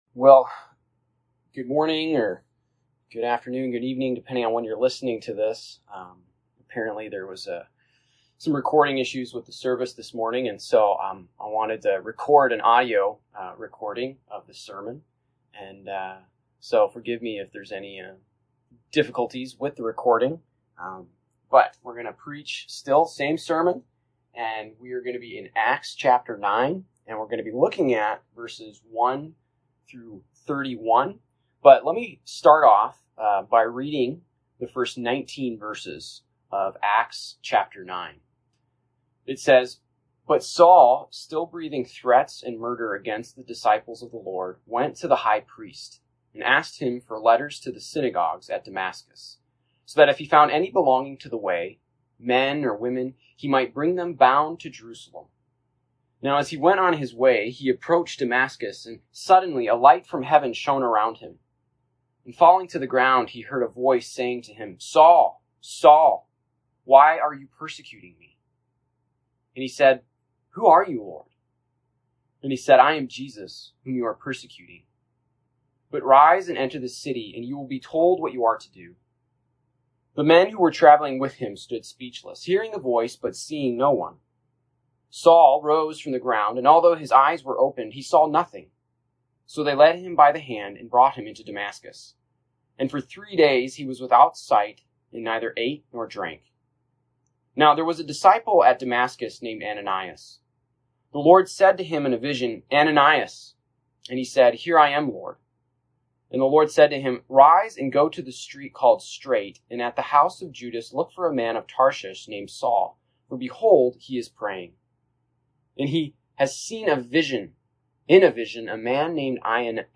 August 22, 2021 Worship Service
Acts 9:1-31 Service Type: Live Service Download Files Notes and/or Discussion Questions « Who Are You Walking With?